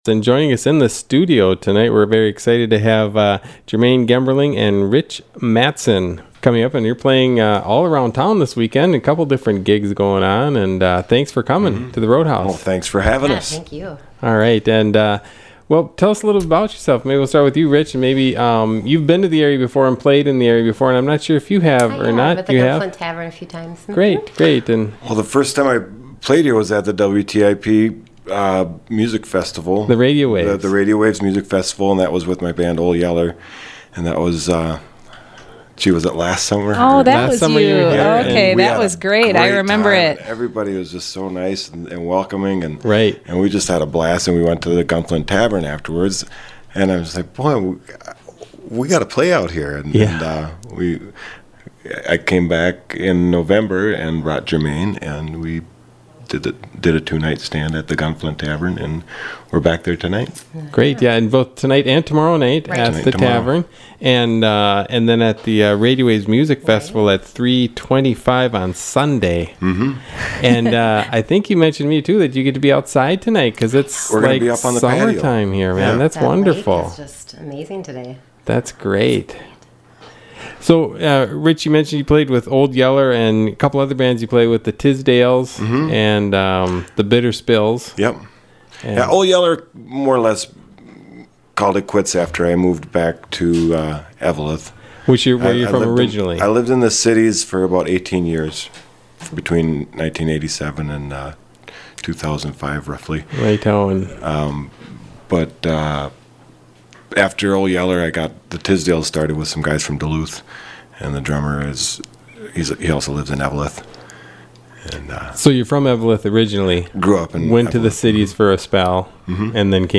conversation and original music